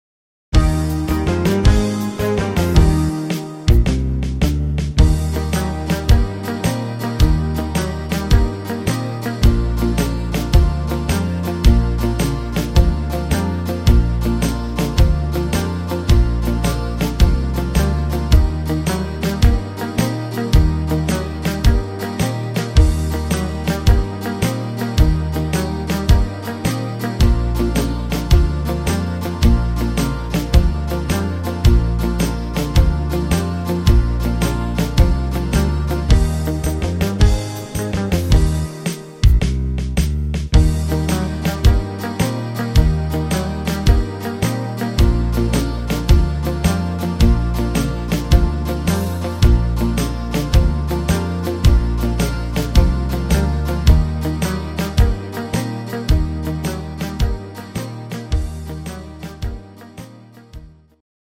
Gesamtkatalog 10892 Playbacks
instr. Saxophon